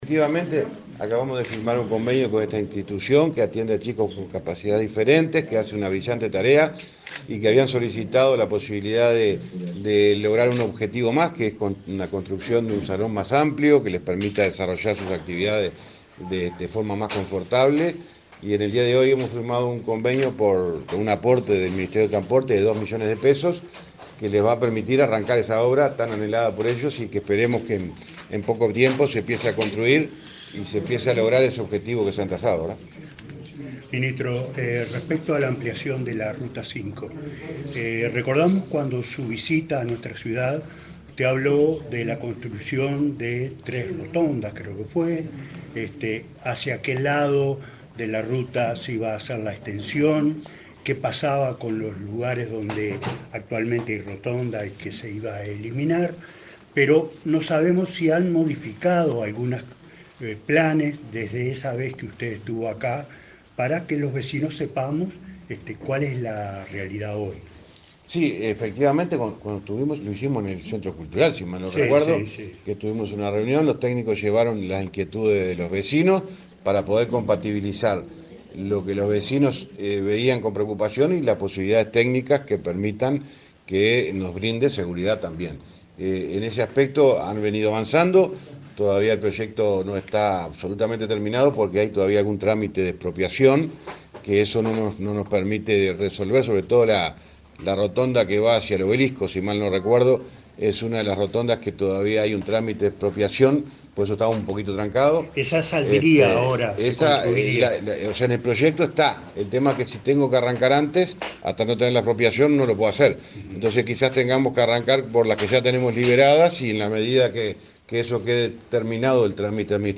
Declaraciones del ministro de Transporte, José Luis Falero
Este jueves 23, el ministro de Transporte, José Luis Falero, dialogó con la prensa, durante una recorrida por Florida y Tacuarembó, donde firmó